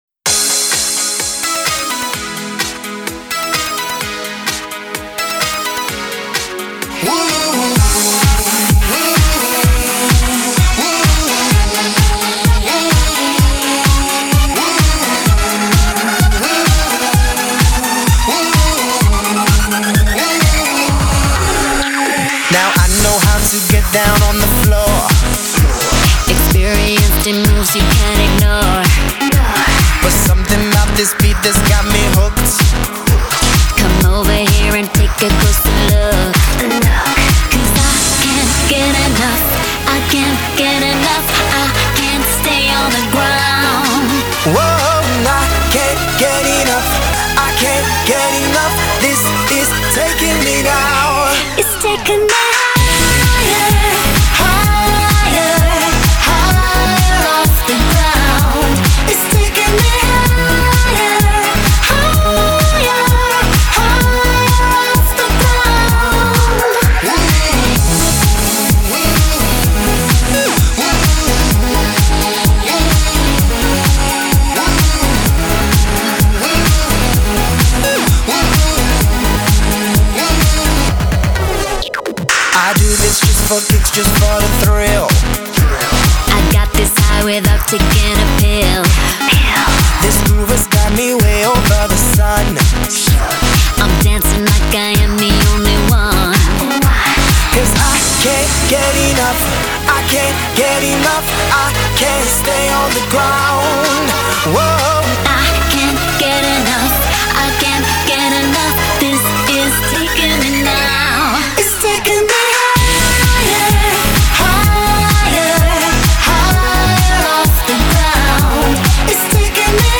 Жанр: Eurodance & POP